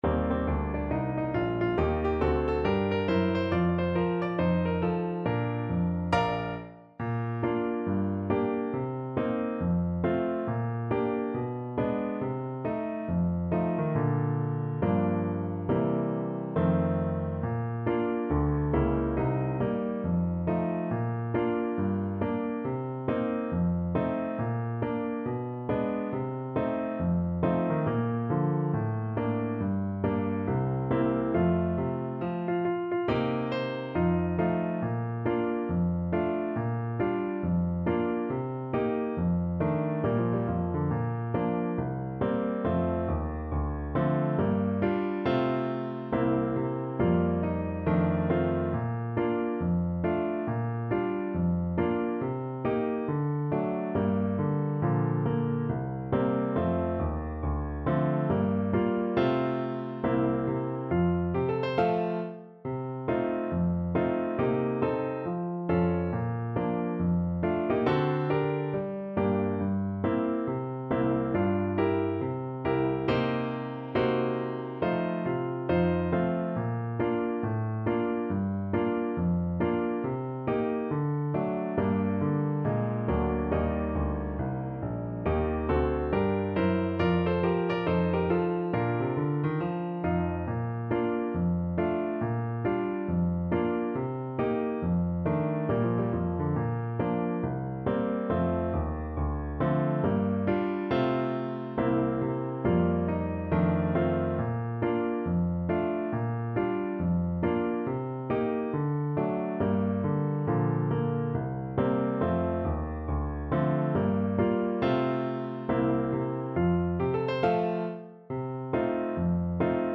With a swing =c.69
4/4 (View more 4/4 Music)
Pop (View more Pop Trumpet Music)